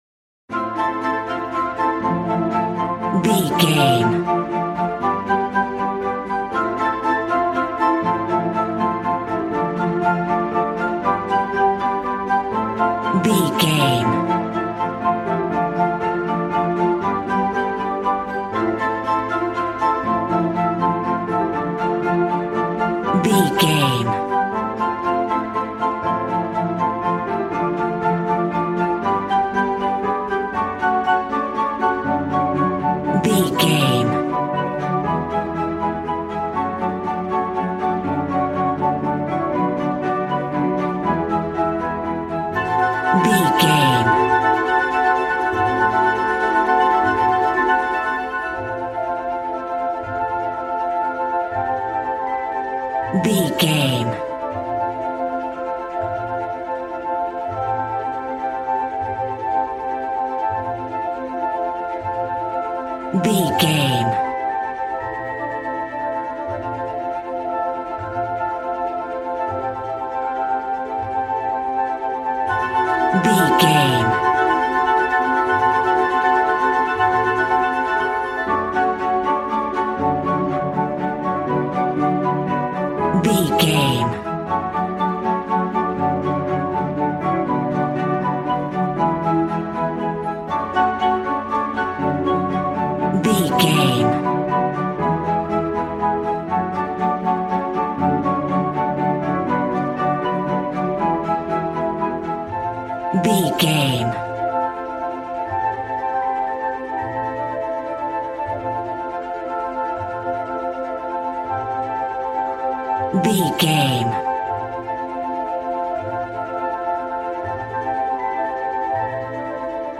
Regal and romantic, a classy piece of classical music.
Aeolian/Minor
DOES THIS CLIP CONTAINS LYRICS OR HUMAN VOICE?
WHAT’S THE TEMPO OF THE CLIP?
strings
violin
brass